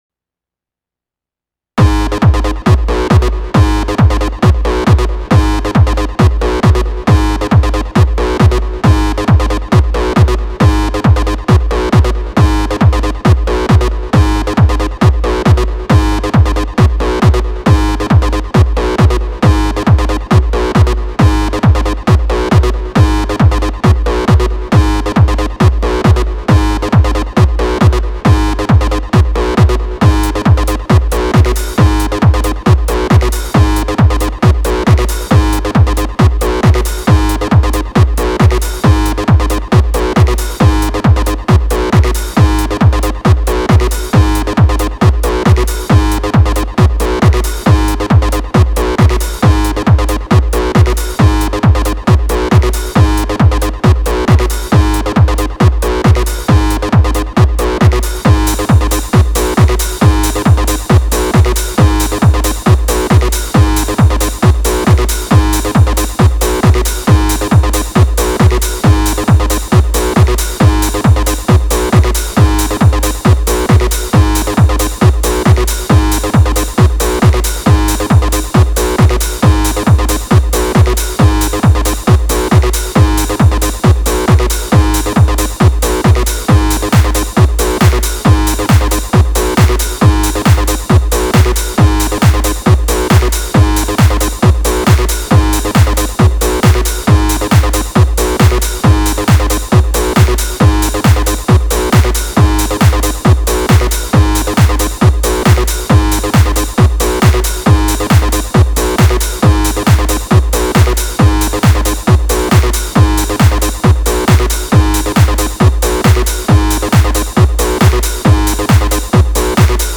Встроенный в 8080 дисторшен.